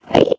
minecraft / sounds / mob / endermen / idle1.ogg